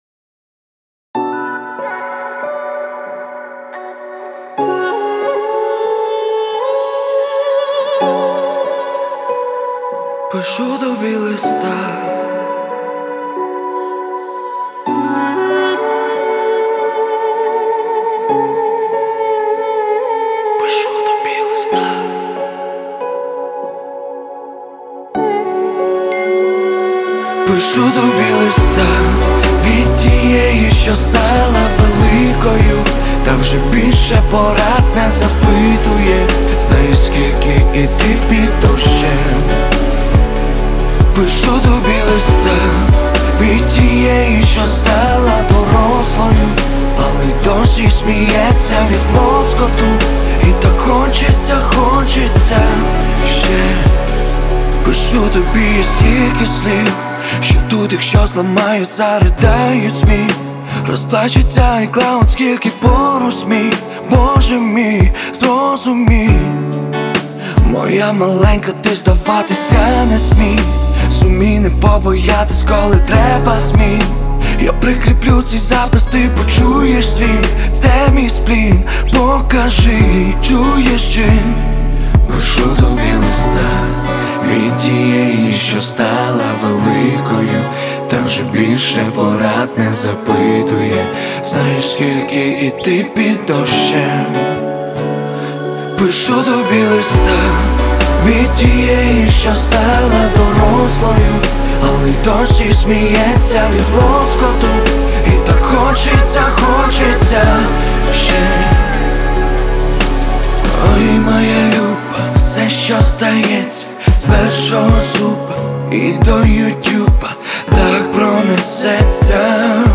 • Жанр:Поп